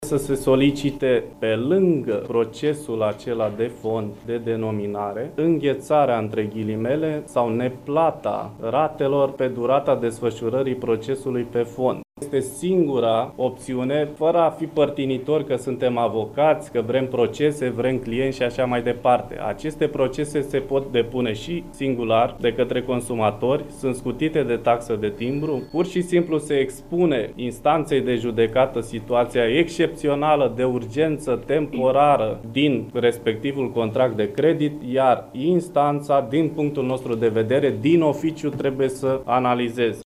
El a explicat, într-o conferinţă de presă, care este soluţia pentru atenuarea efectelor negative ale creşterii monedei elveţiene: